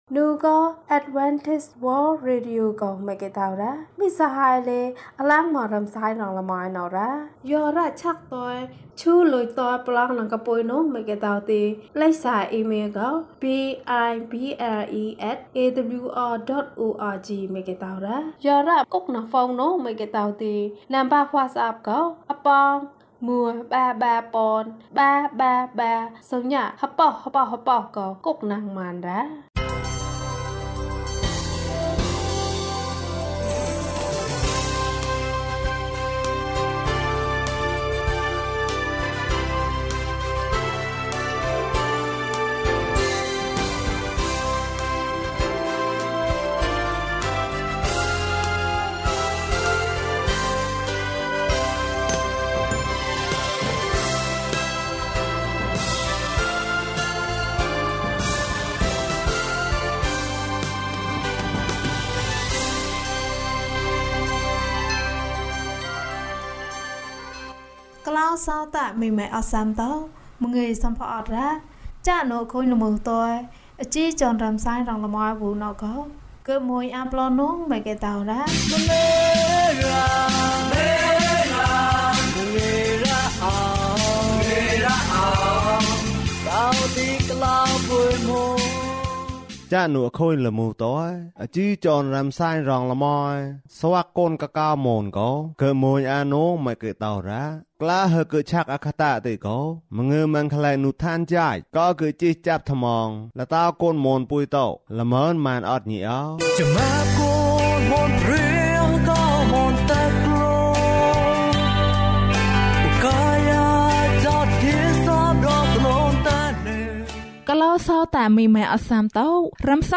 အံ့ဩဘွယ်သောဘုရား။ ကျန်းမာခြင်းအကြောင်းအရာ။ ဓမ္မသီချင်း။ တရားဒေသနာ။